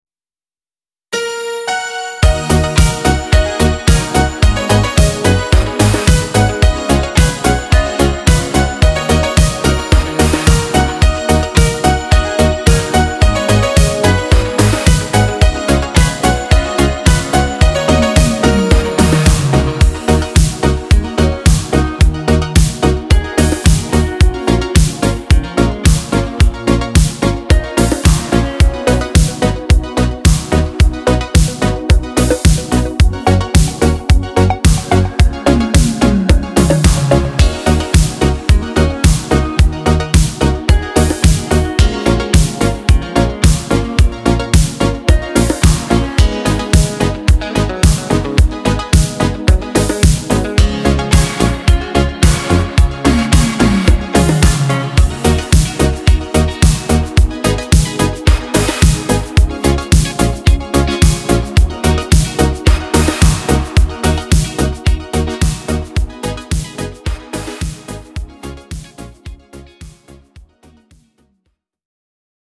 w nowej aranżacji jako podkład dla wokalistów
Dance